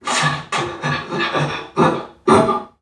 NPC_Creatures_Vocalisations_Robothead [23].wav